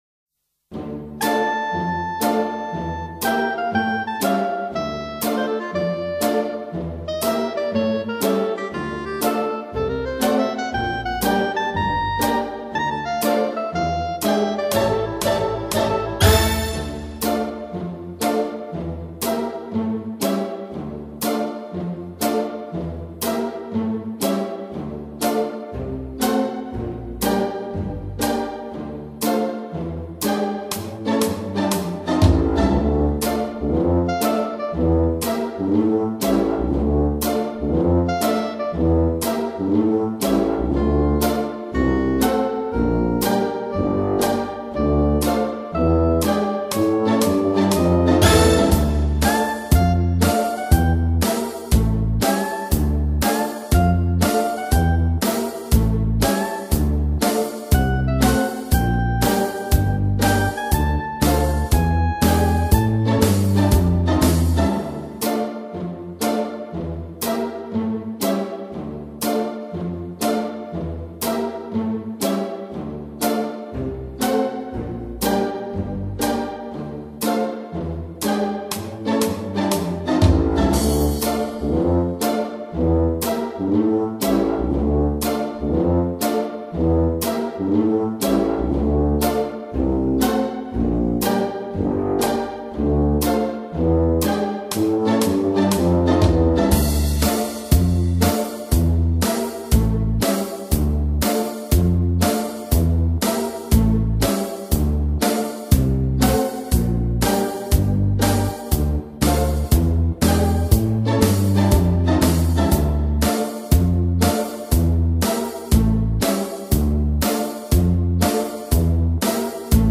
HALLO-DJANGO-Base-instrumental-1.mp3